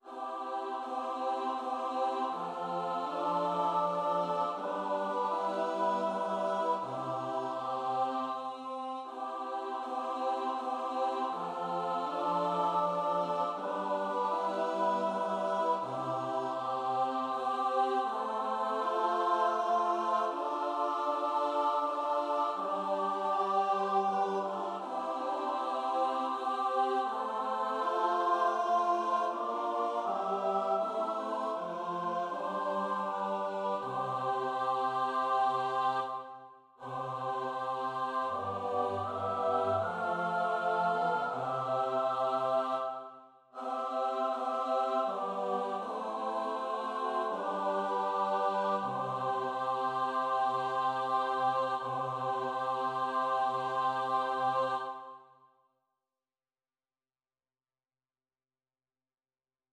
..... is a short unaccompanied piece in your folders.